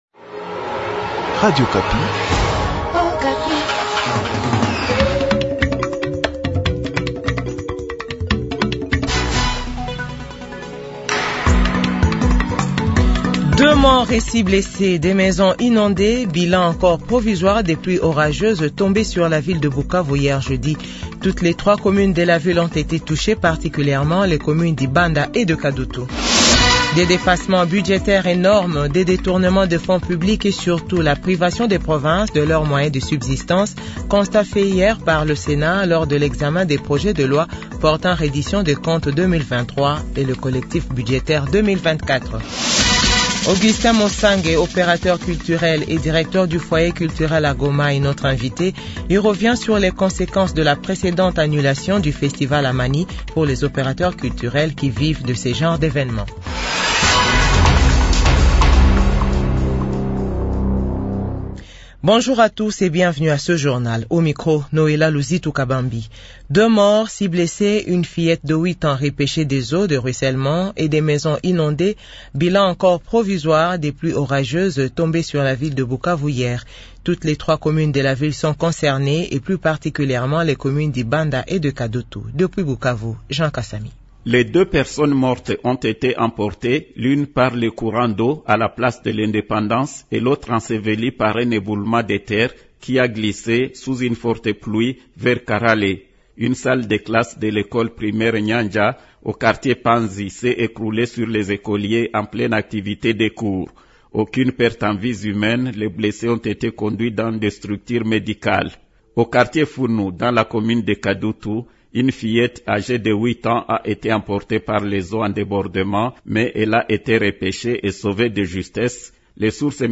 JOURNAL FRANÇAIS 12H00